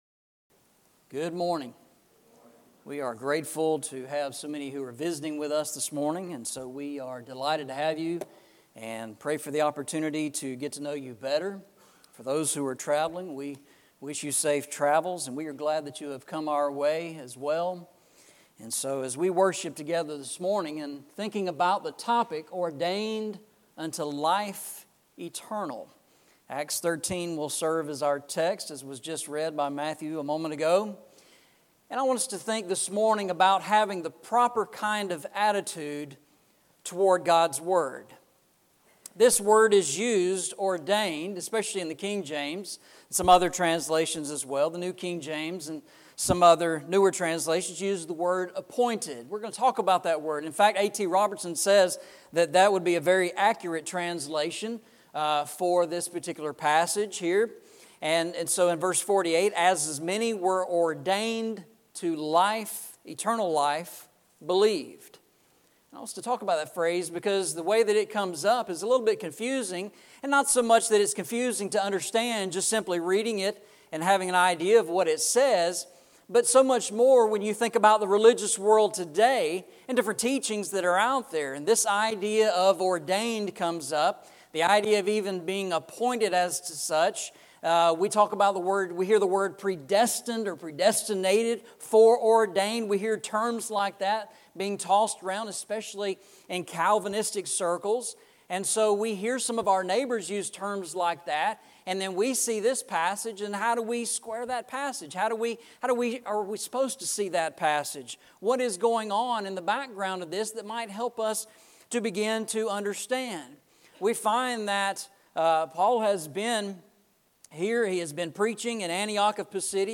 Eastside Sermons Passage: Acts 13:44-49 Service Type: Sunday Morning « Life’s Navigation Aids Walking Through the Bible